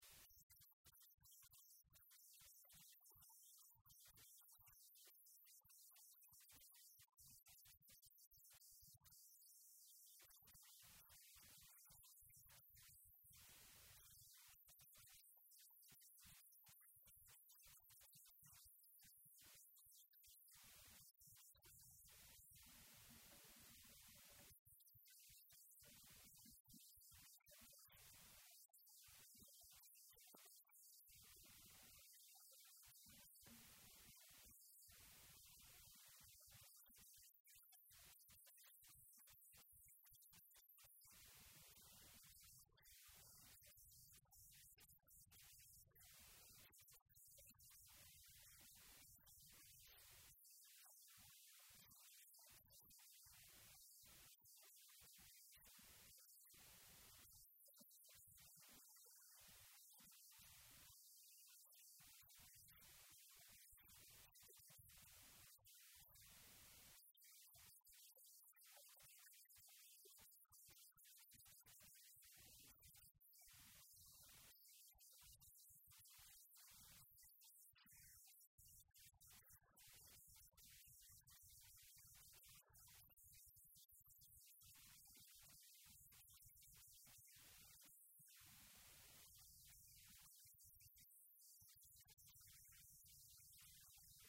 Pequenos divertem-se na festa de Natal das escolas